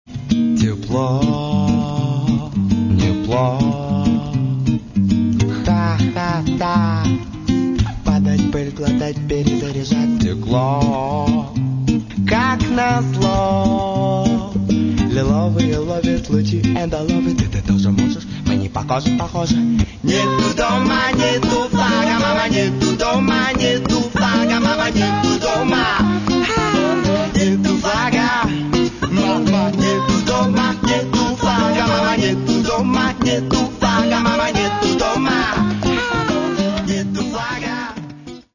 Каталог -> Рок и альтернатива -> Регги